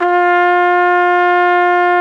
Index of /90_sSampleCDs/Roland L-CD702/VOL-2/BRS_Tpt 5-7 Solo/BRS_Tp 6 AKG Jaz